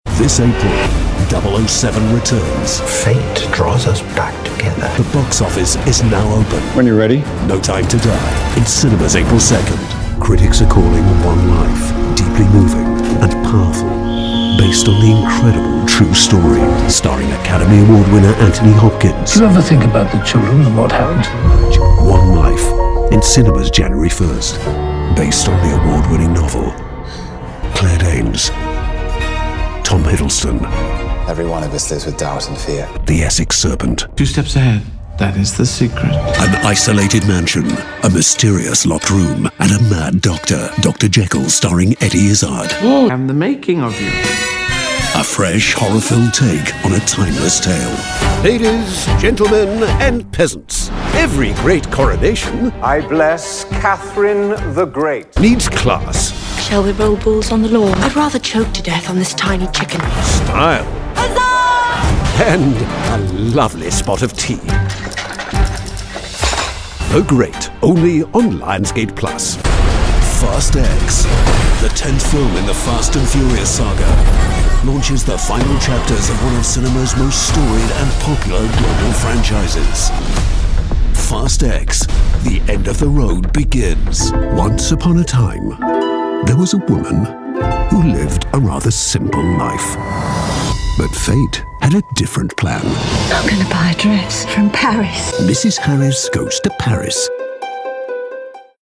Trailers de filmes
Minha voz é geralmente descrita como calorosa, natural e distinta e é frequentemente usada para adicionar classe e sofisticação a projetos de publicidade e narrativa.